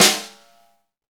Index of /90_sSampleCDs/Club-50 - Foundations Roland/KIT_xExt.Snare 4/KIT_xExt.Snr 4dS
SNR XEXTS0JR.wav